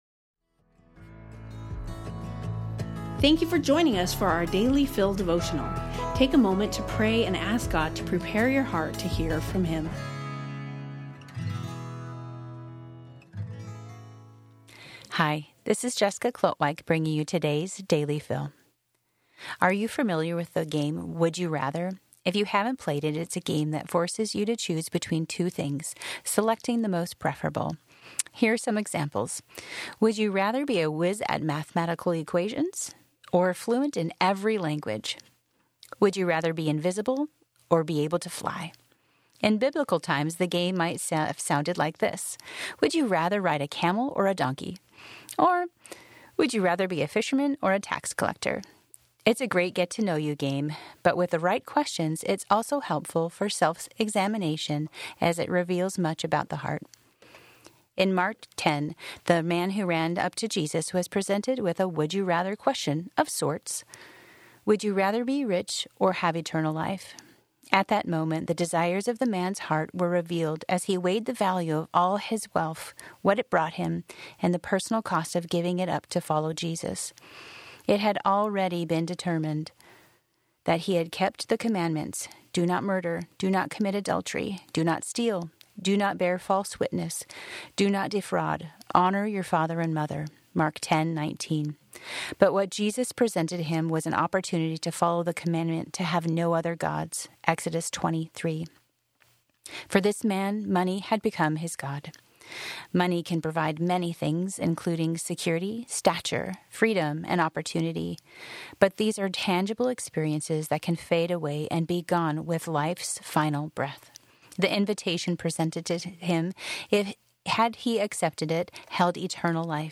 We hope you will enjoy these audio devotionals.